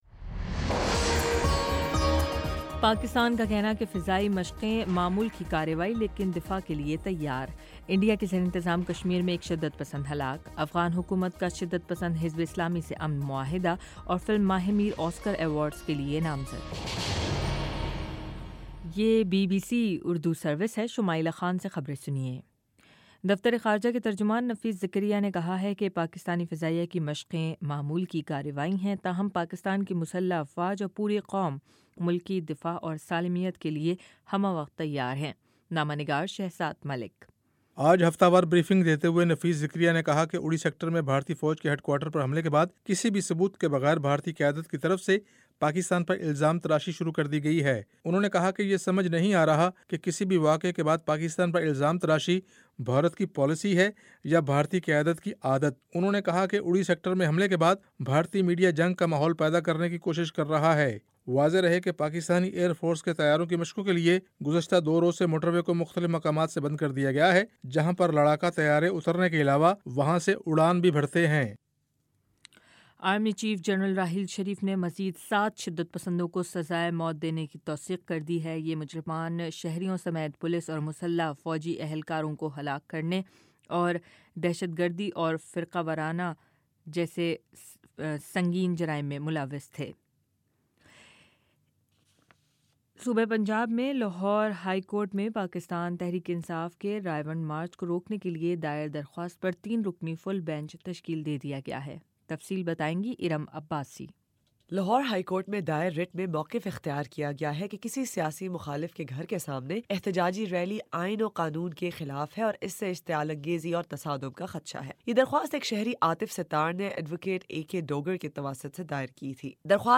ستمبر22 : شام سات بجے کا نیوز بُلیٹن